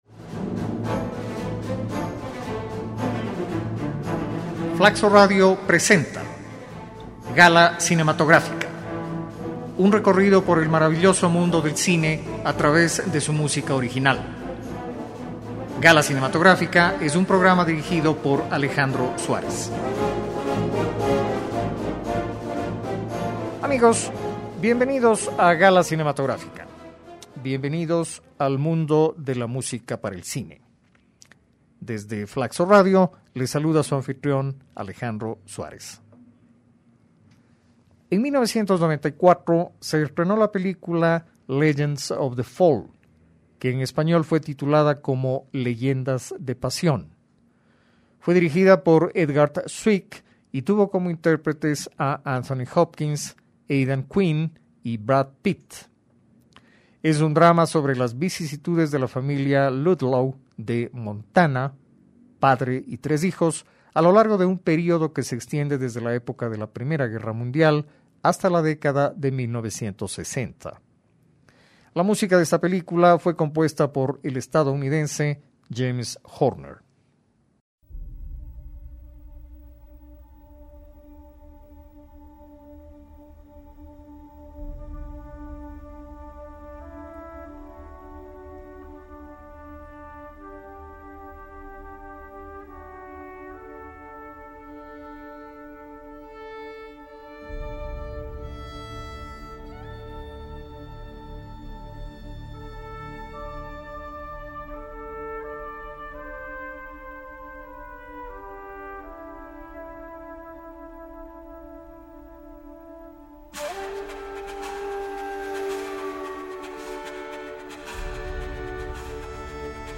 una música bien planteada, expresiva y rica en matices